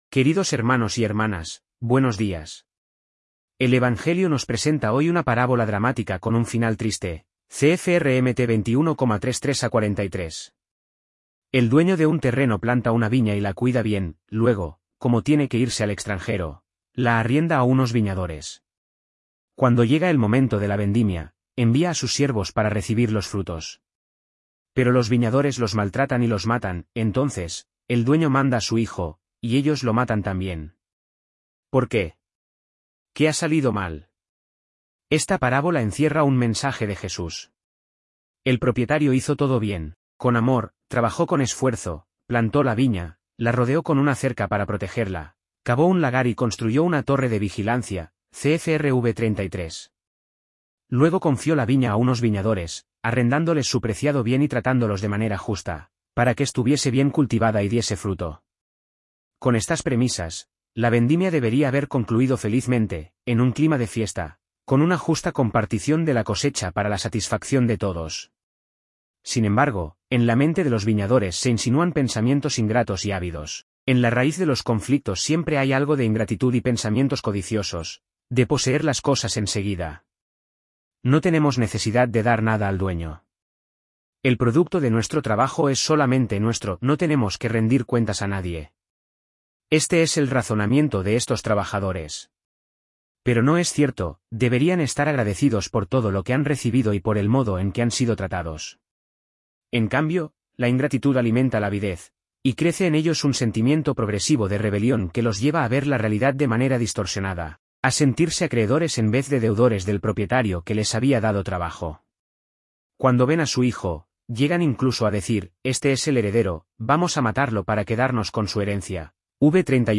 Palabras del Papa en el Ángelus
A las 12 del mediodía de este domingo, 8 de octubre de 2023, el Santo Padre Francisco se asomó a la ventana del estudio del Palacio Apostólico Vaticano para recitar el Ángelus con los fieles y peregrinos congregados en la Plaza de San Pedro.